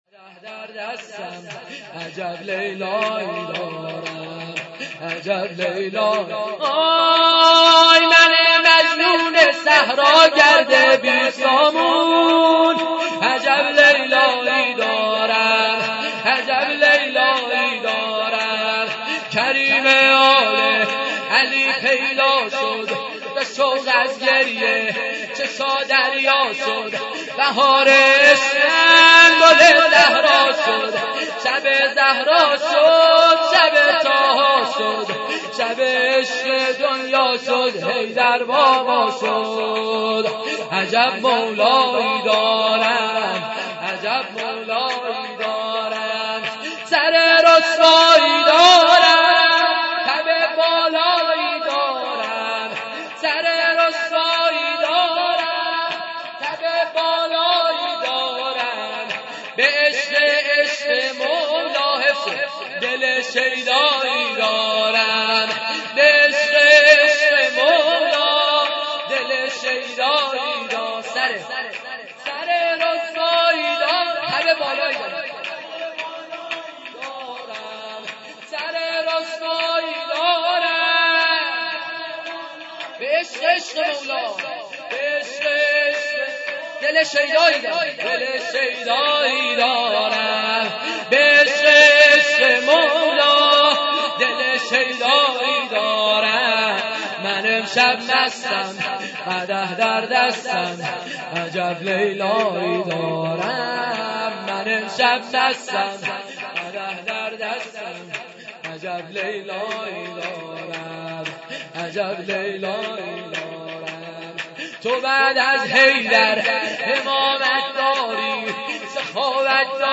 جشن میلاد امام حسن مجتبی